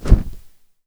wing-flap-Big.wav